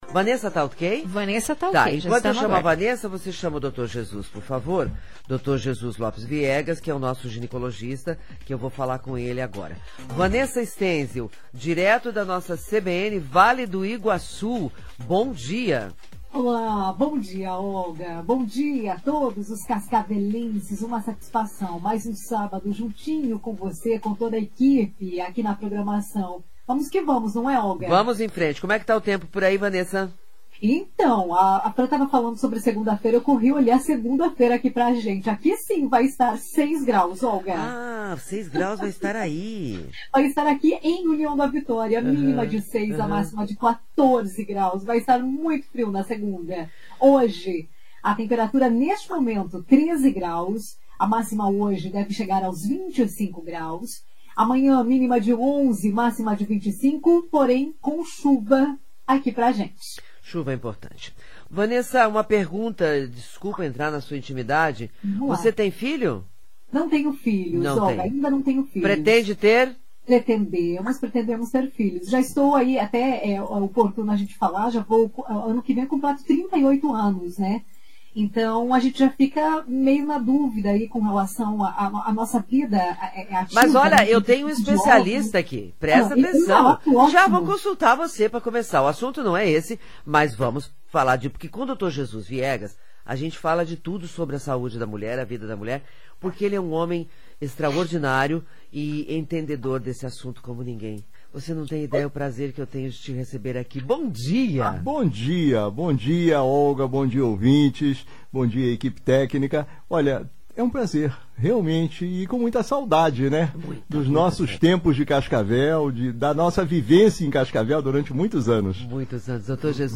Em entrevista à CBN Cascavel